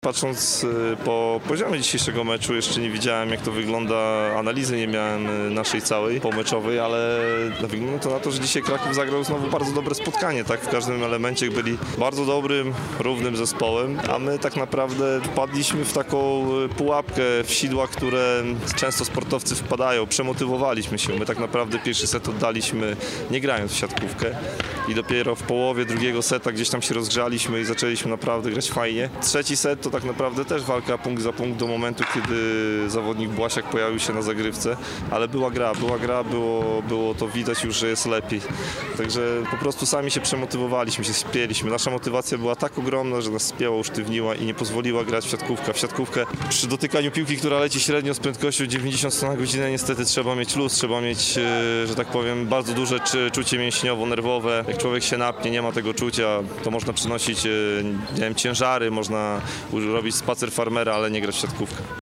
na gorąco, tuż po spotkaniu